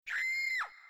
Download Woman Scream sound effect for free.
Woman Scream